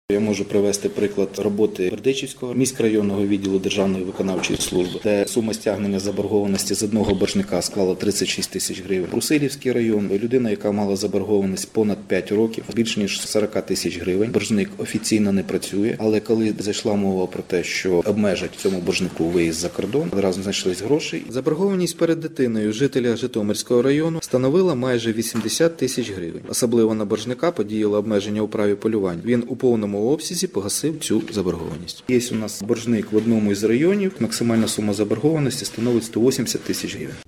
Про це Українському раідо повідомив начальник головного територіального управління юстиції у Житомирській області Олександр Грабар.